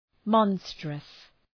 Προφορά
{‘mɒnstrəs} (Επίθετο) ● τερατώδης